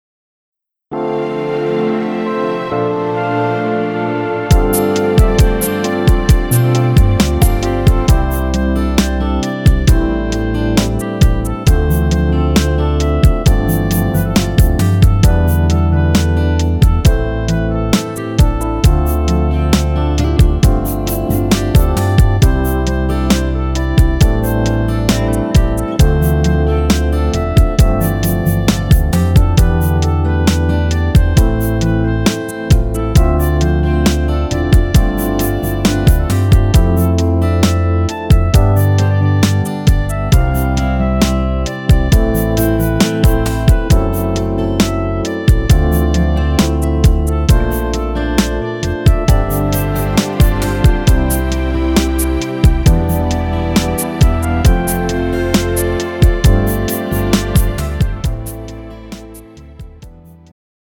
음정 -2키
장르 축가 구분 Pro MR